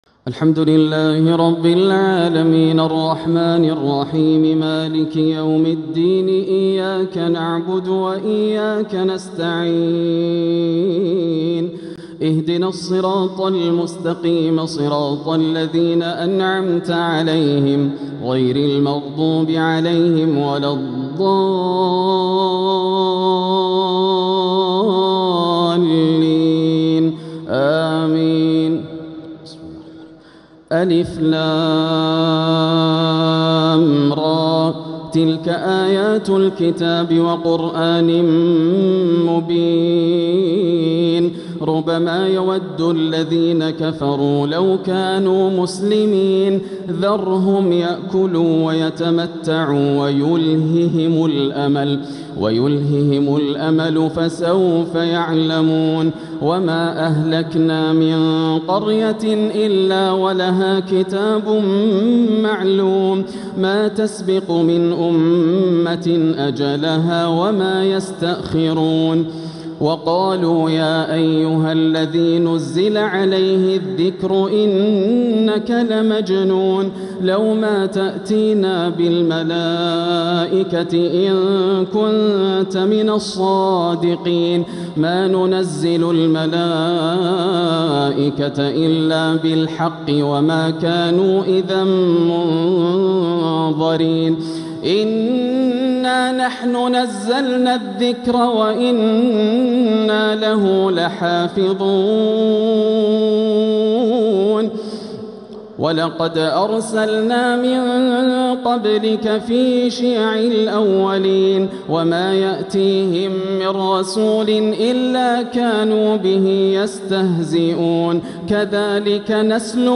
سورة الحجر كاملة | تهجد ليلة 29 رمضان 1447هـ > الليالي الكاملة > رمضان 1447 هـ > التراويح - تلاوات ياسر الدوسري